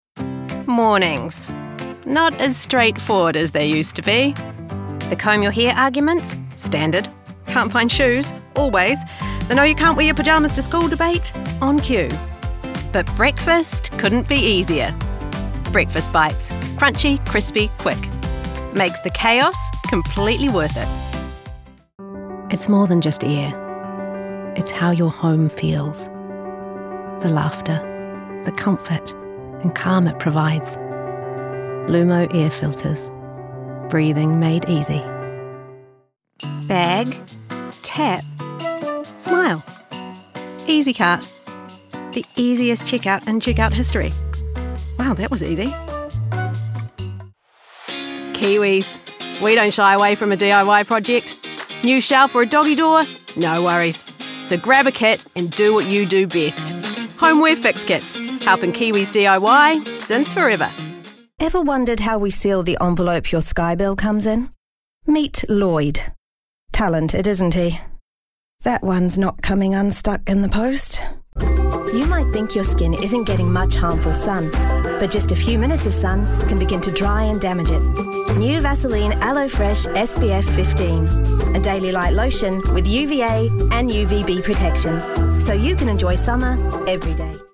Demo
new zealand | natural
warm/friendly